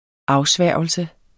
Udtale [ ˈɑwˌsvæɐ̯ˀwəlsə ]